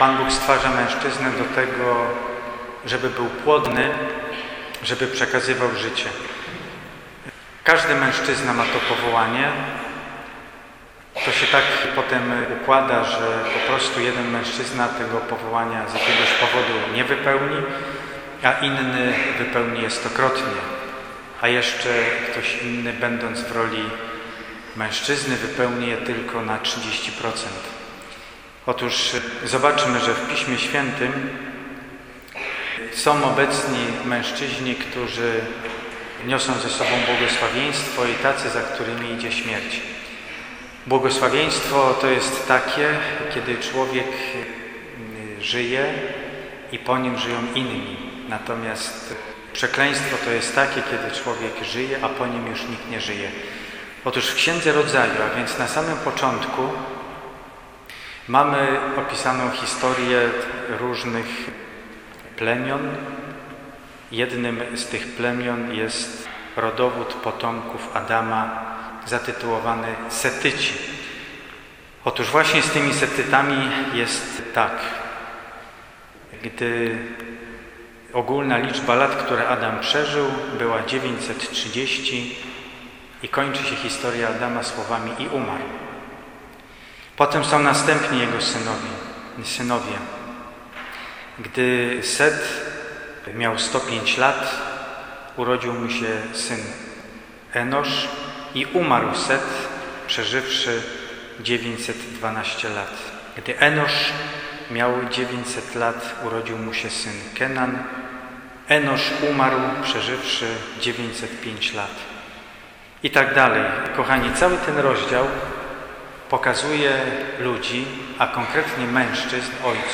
Homilia
W każdy drugi piątek miesiąca w naszym kościele odprawiana jest Msza św. z modlitwą przebłagalną za grzechy przeciwko godności mężczyzny i kobiety, prymatowi ojcostwa, świętości życia i rodziny.